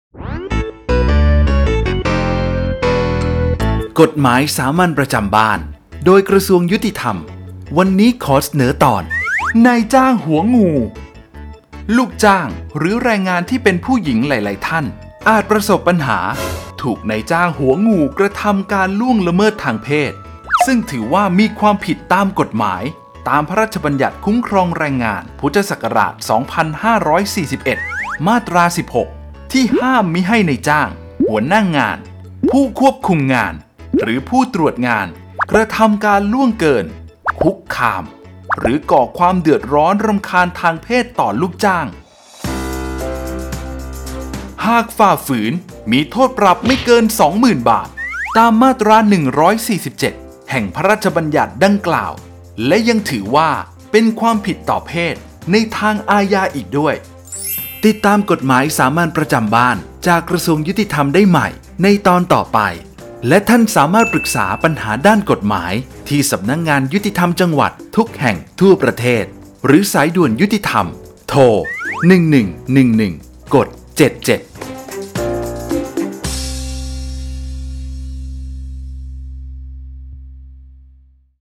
กฎหมายสามัญประจำบ้าน ฉบับภาษาท้องถิ่น ภาคกลาง ตอนนายจ้างหัวงู
ลักษณะของสื่อ :   คลิปเสียง, บรรยาย